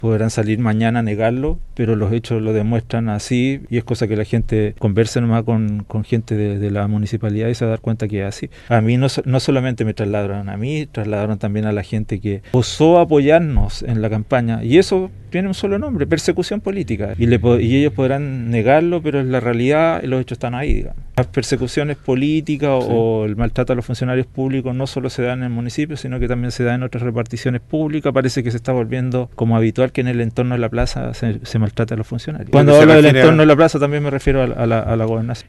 En entrevista exclusiva con radio Sago el candidato a Diputado por el distrito 26